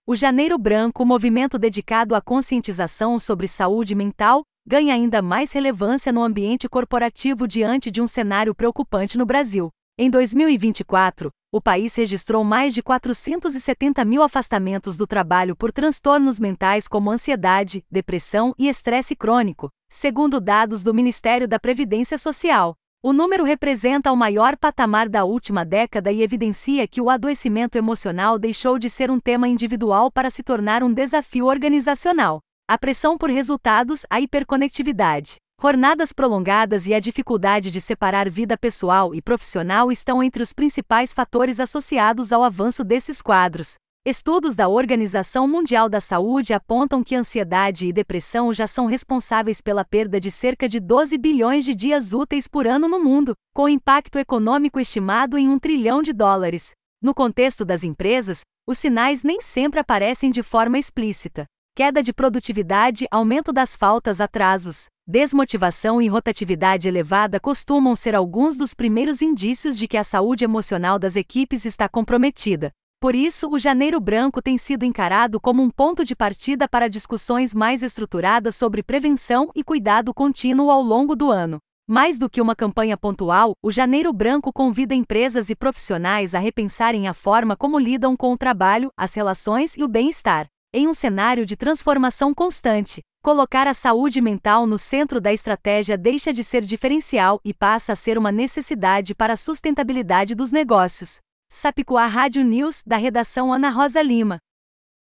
Boletins de MT 05 jan, 2026